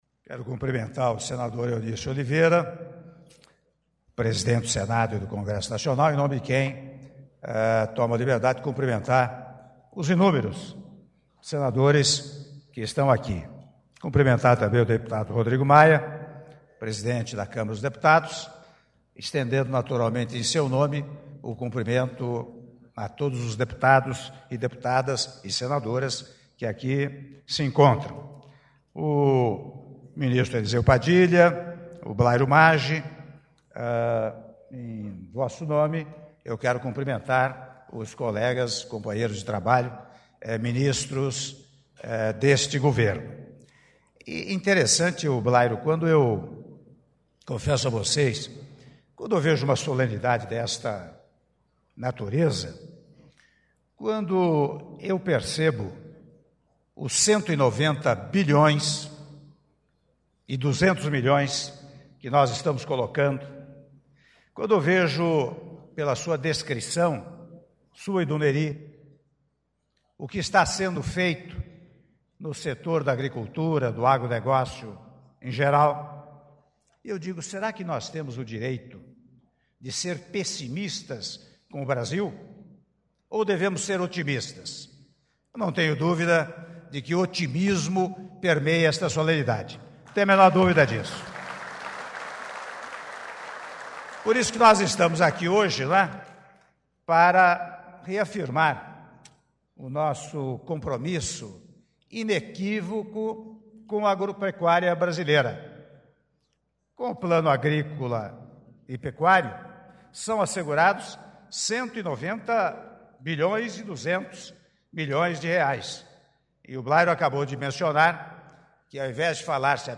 Áudio do discurso do presidente da República, Michel Temer, durante cerimônia de Lançamento do Plano Agrícola e Pecuário 2017/2018- Brasília/DF- (07min08s)